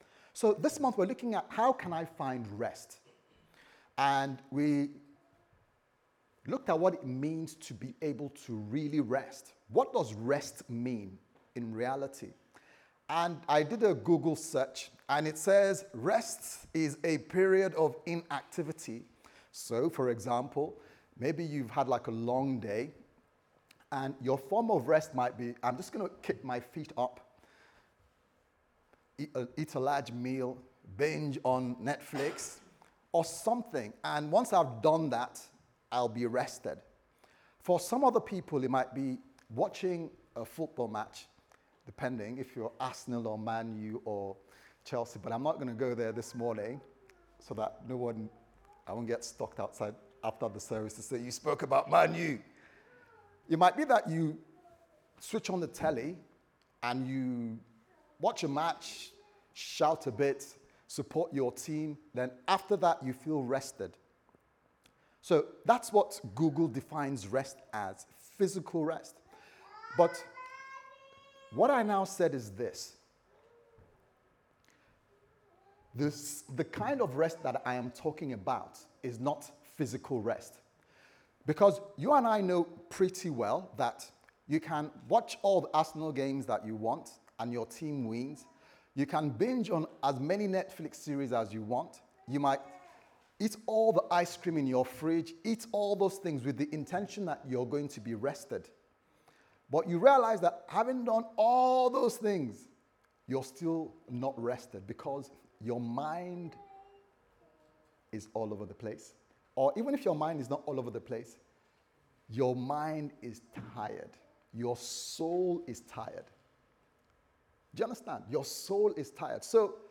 How Can I Find Rest Service Type: Sunday Service Sermon « How Can I Find Rest